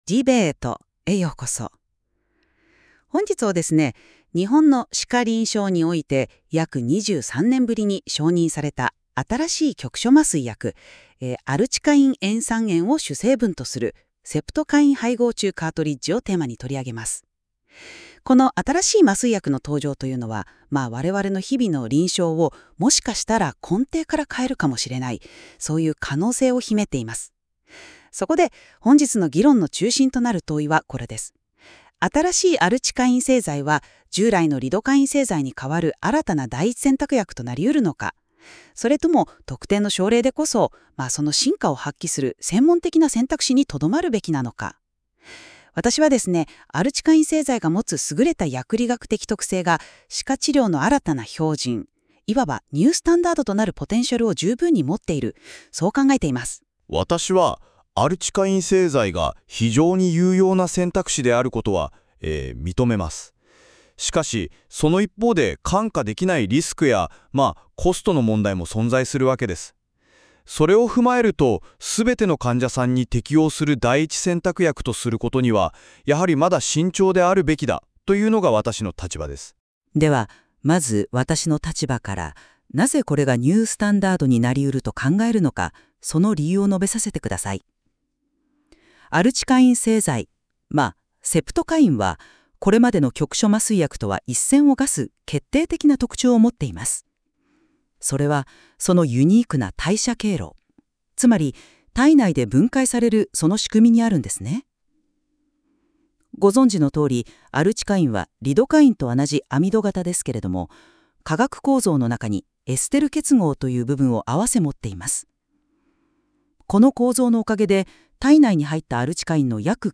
解説音声 新規局所麻酔薬アルチカインは標準か専門薬か